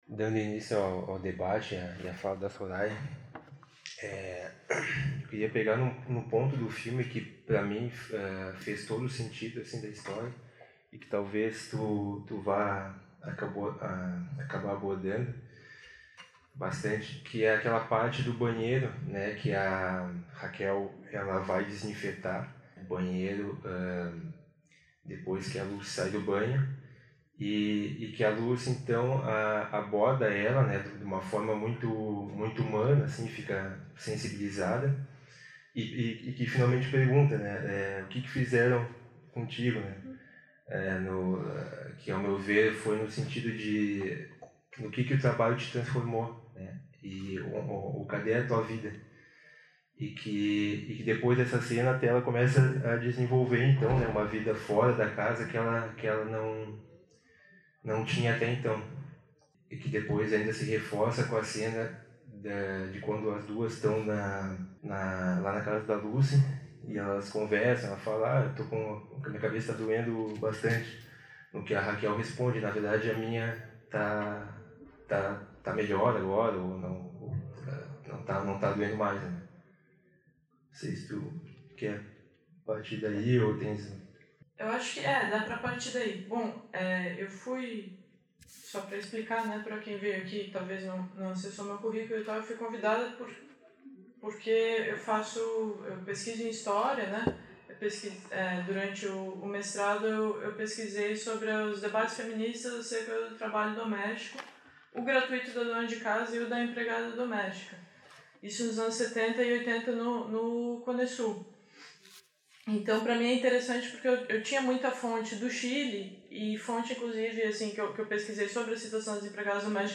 realizada em 19 de novembro de 2015 no Auditório "Elke Hering" da Biblioteca Central da UFSC